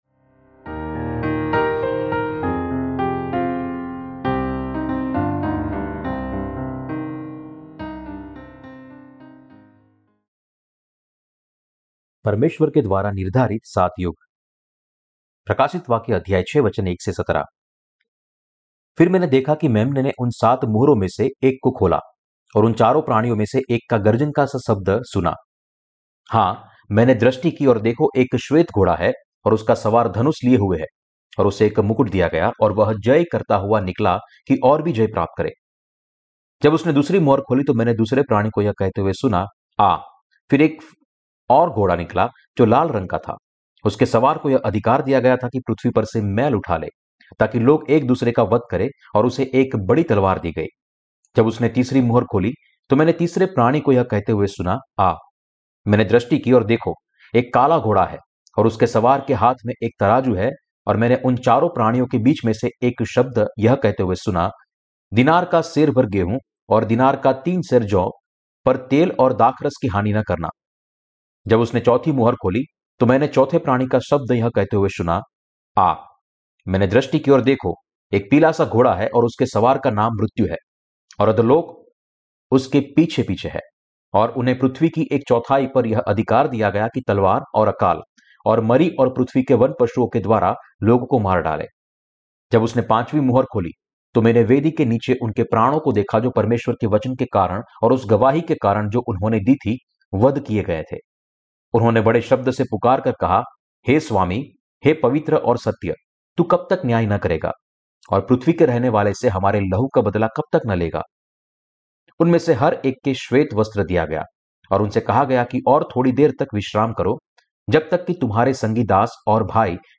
प्रकाशितवाक्य की किताब पर टिप्पणी और उपदेश - क्या मसीह विरोधी, शहादत, रेप्चर और हजार साल के राज्य का समय नज़दीक है?